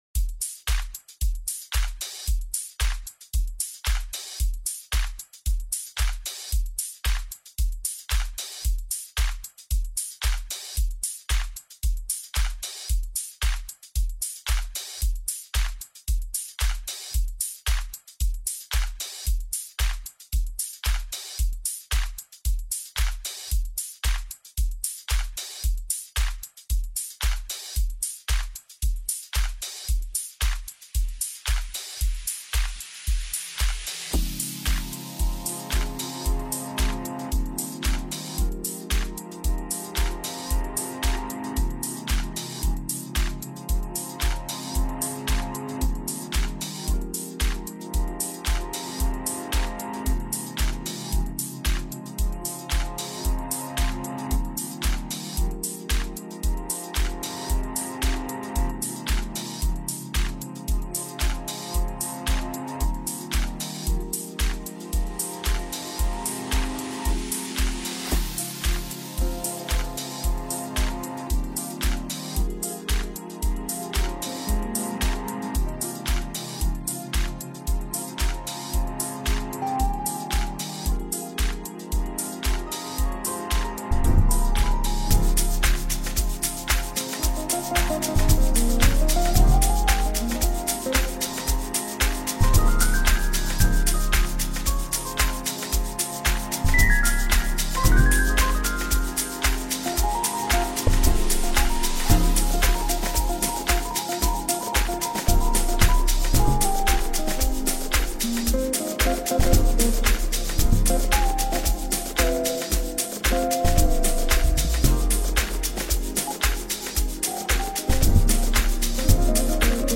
Amapiano
uplifting and euphoric style